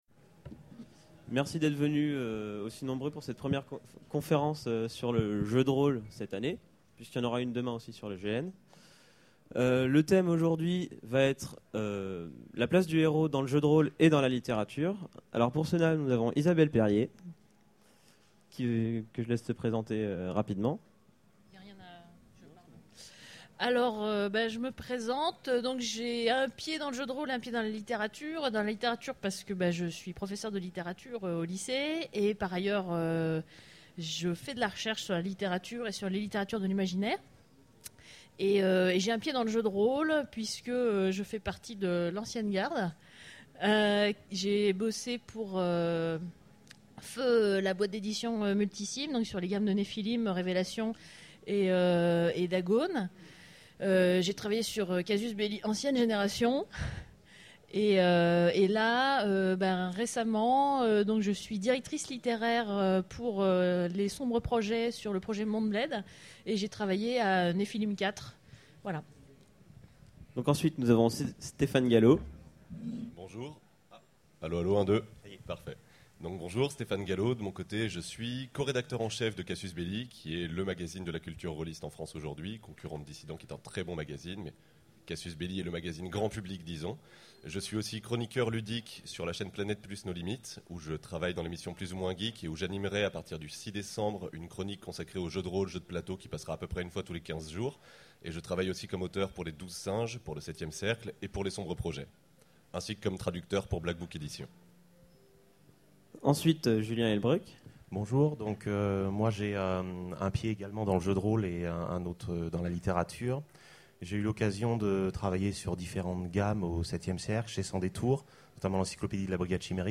Utopiales 12 : Conférence Jeu de rôle et littérature
Conférence